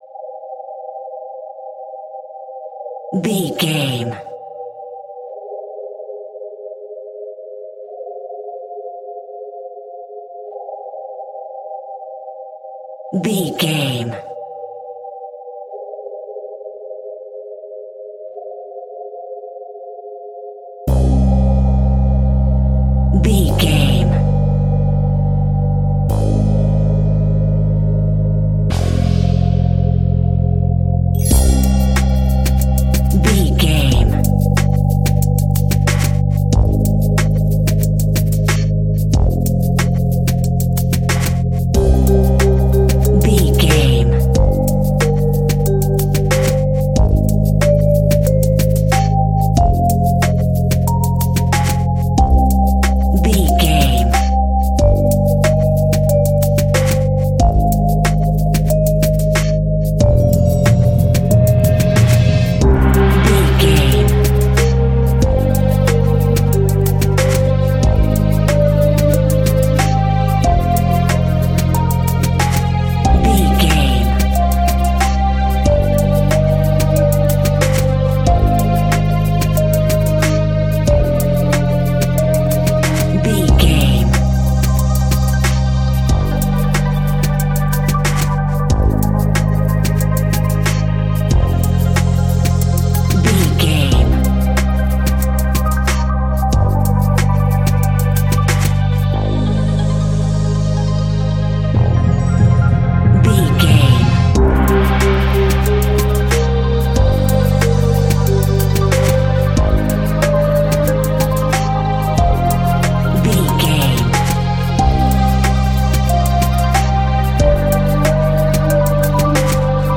Halloween Hip Hop.
Aeolian/Minor
ominous
dramatic
eerie
synthesiser
drums
bass guitar
percussion
spooky